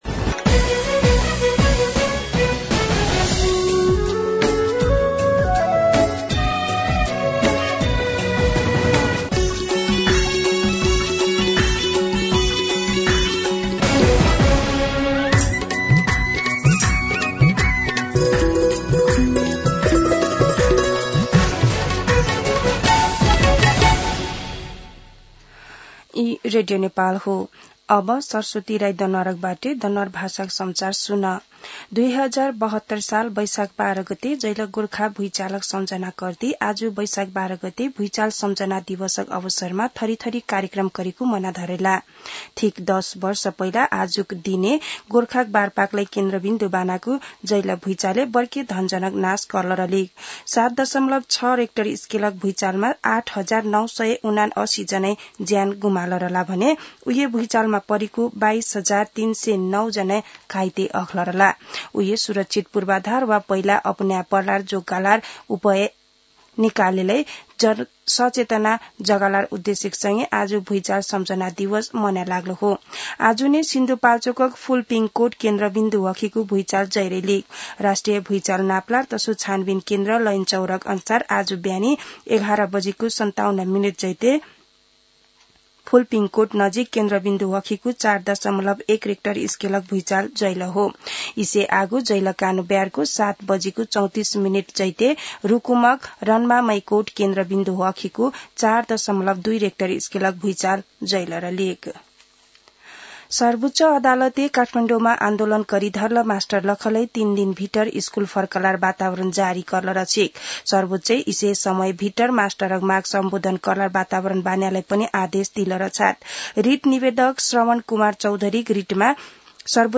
दनुवार भाषामा समाचार : १२ वैशाख , २०८२
Danuwar-News-11.mp3